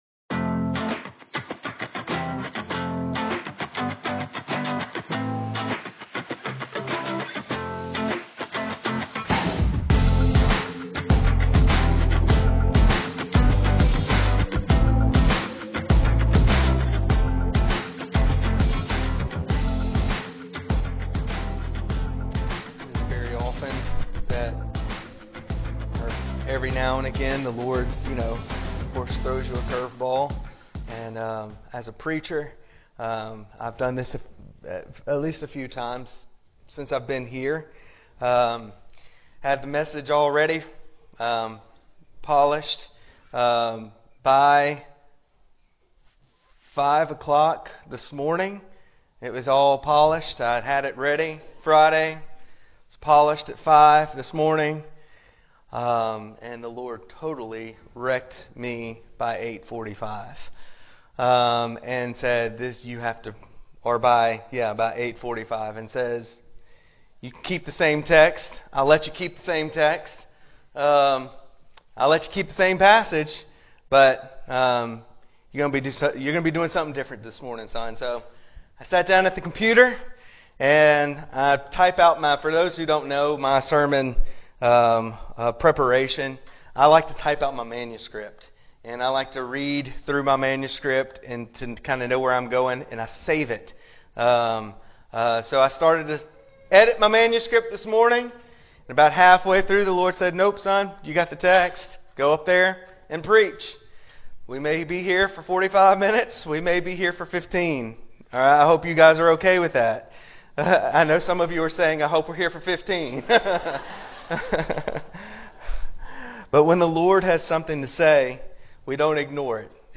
Sermons | Trace Creek Baptist Church